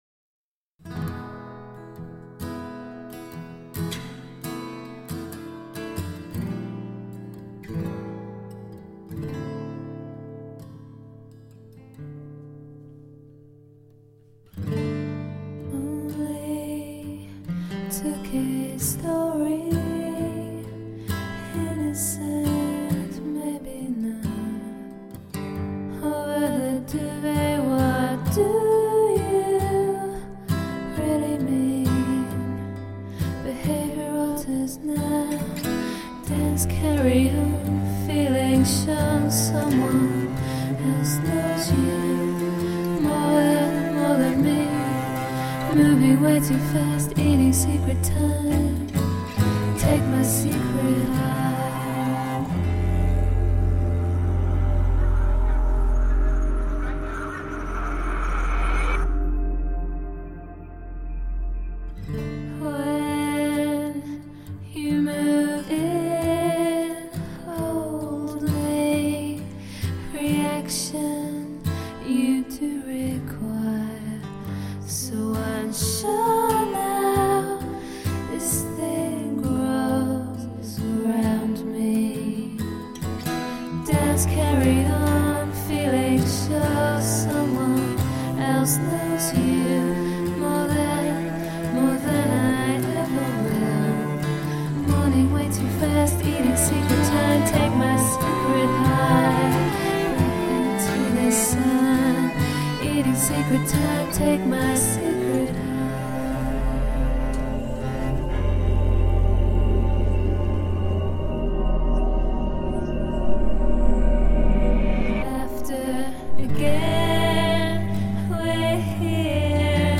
Tagged as: Alt Rock, Pop